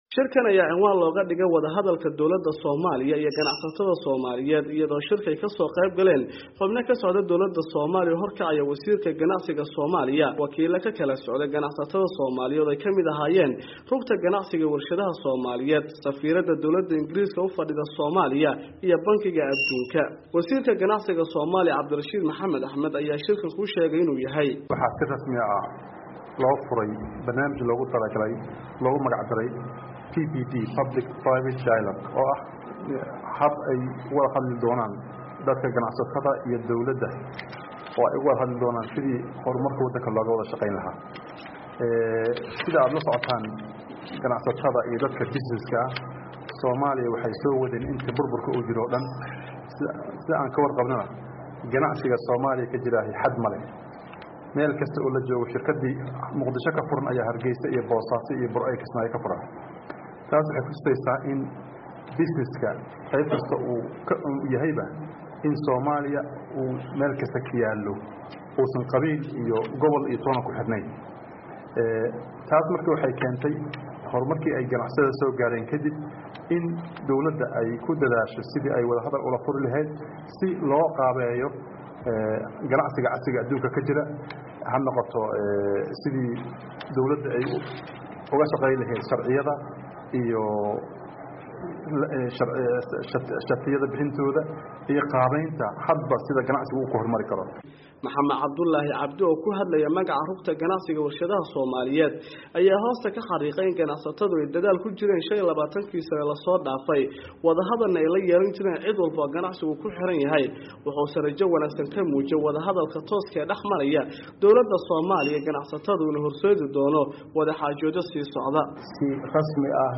War Deg-Deg ah